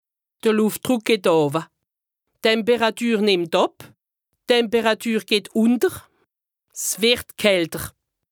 Haut Rhin
Ville Prononciation 68
2APRESTA_OLCA_LEXIQUE_METEO_AIR_HAUT_RHIN_122_0.mp3